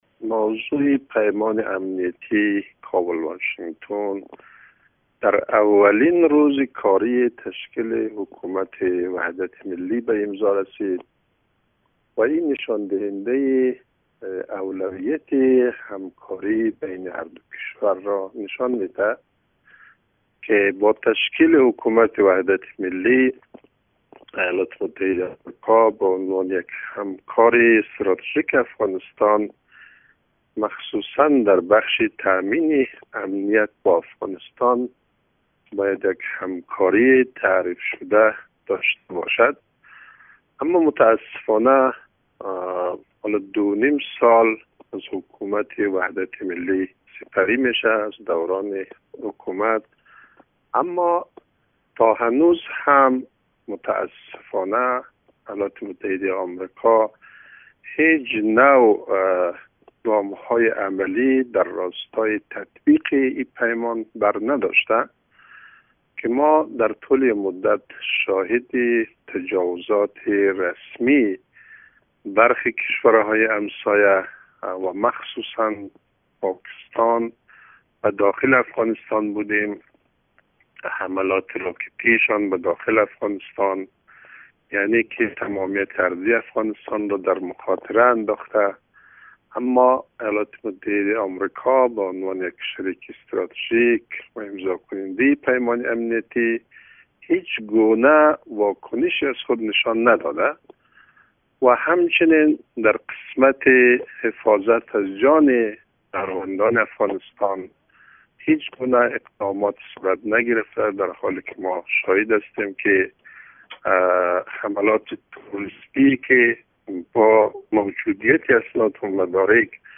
در گفت و گو با خبرنگار رادیو دری